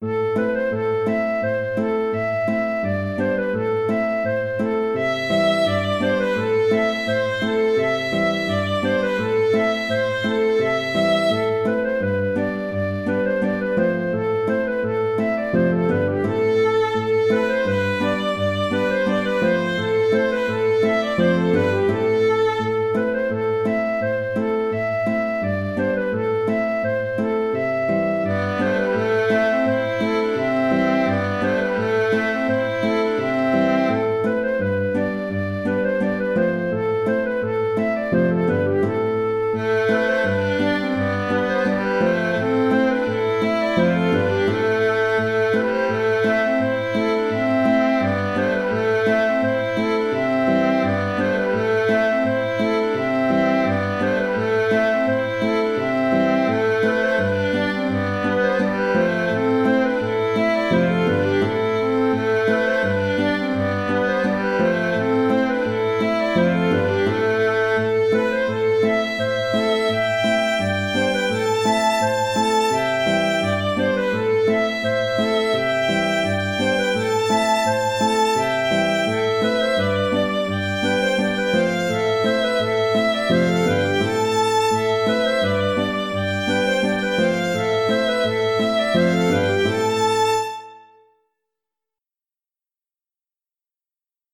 An dro Gwendal (An dro) - Musique bretonne
Cet an dro est un traditionnel qui a fait partie du répertoire du groupe « Gwendal » dans les années 70.
Là encore, je propose deux contrechants à jouer alternativement (ne pas superposer)..